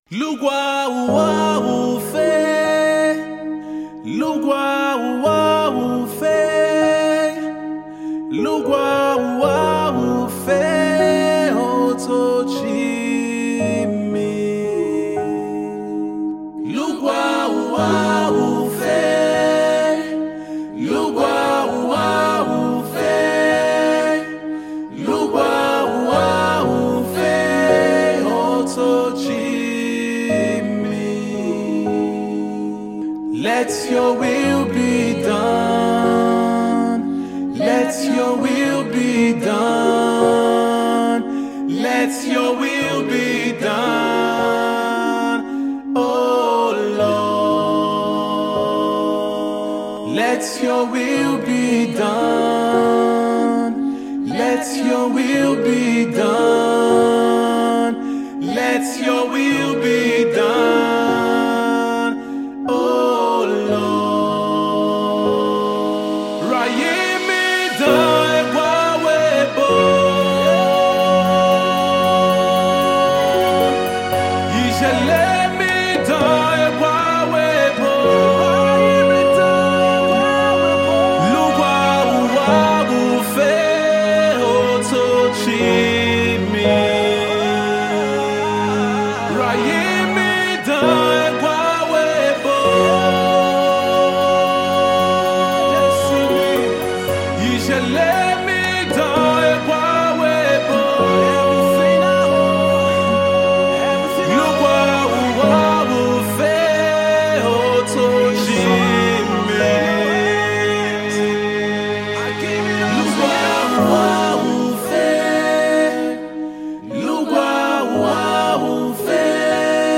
in Gospel Music
soulful tune